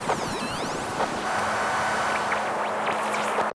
nerfs_psynoise9.ogg